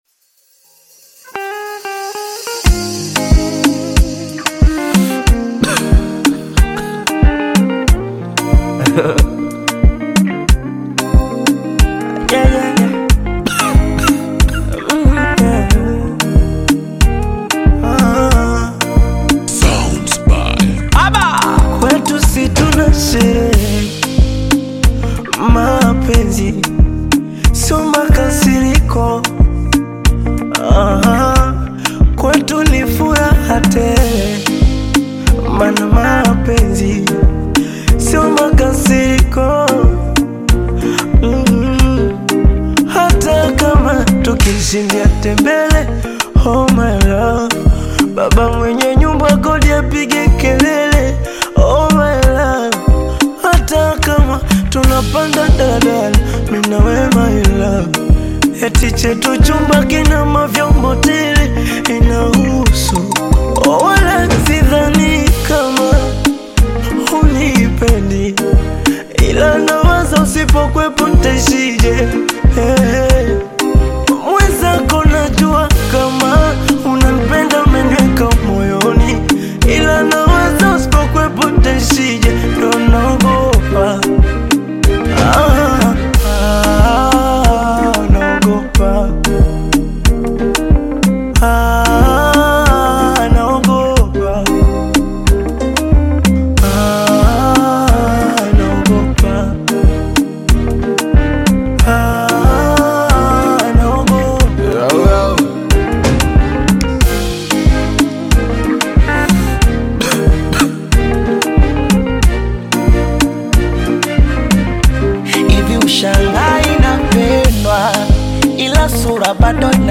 Tanzanian fast-rising Bongo Fleva recording artist
Bongo Flava musician